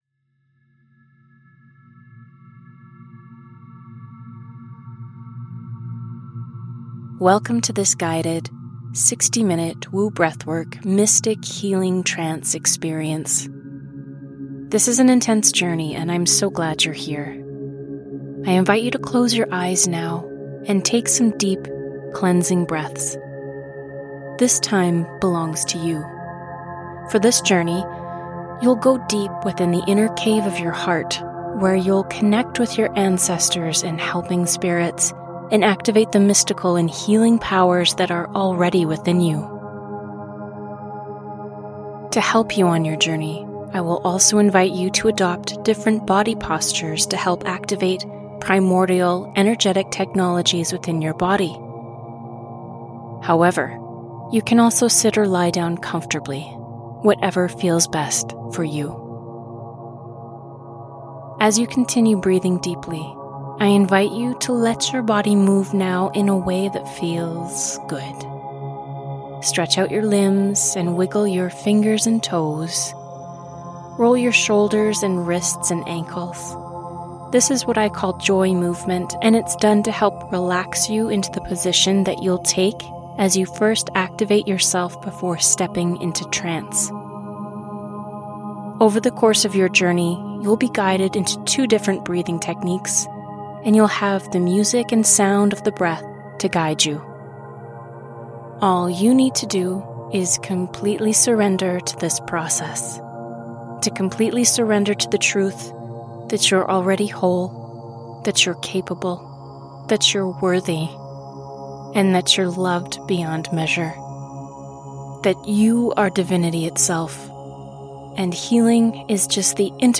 Mystic Healing Trance: A 60-minute Guided Breathwork Journey
Designed for those ready to release the weight of their emotional burdens, this intense experience blends powerful breathwork techniques, mystical postures, and soul-stirring music to guide you through deep transformation.
Audio contains breathing sounds and is best experienced with headphones.